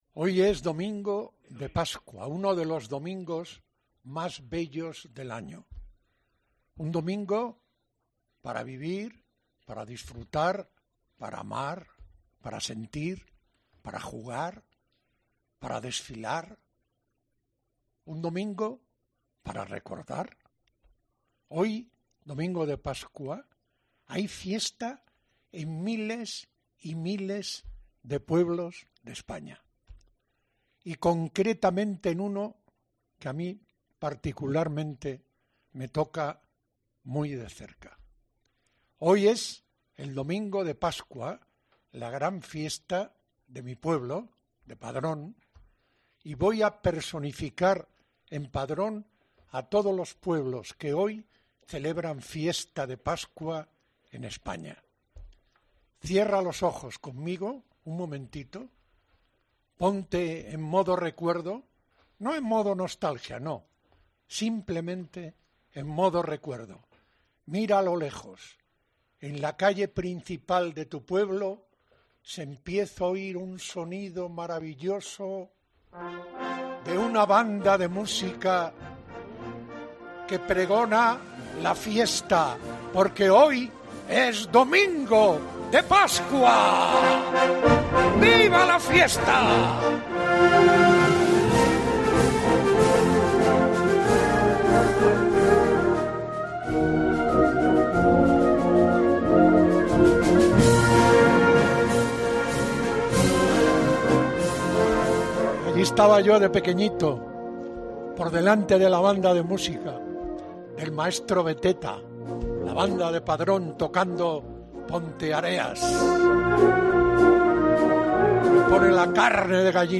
El periodista de la Cadena COPE es una de las voces más destacadas de la radio deportiva española